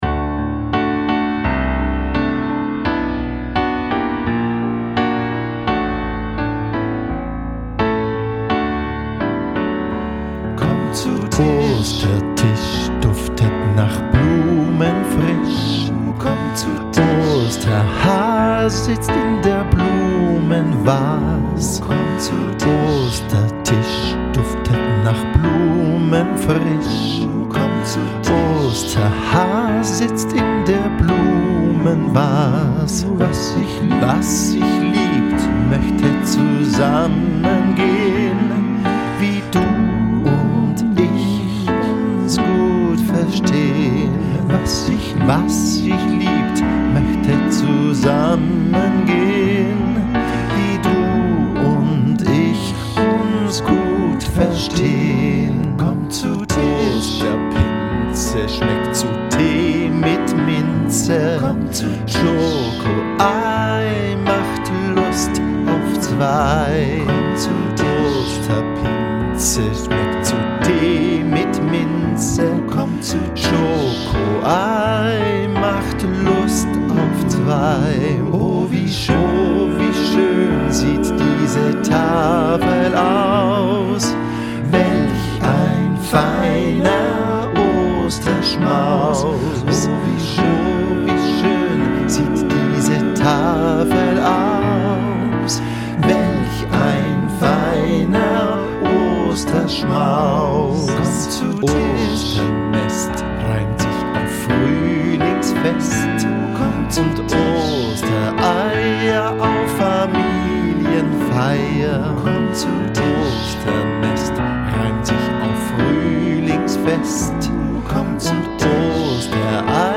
Klavier und Gesang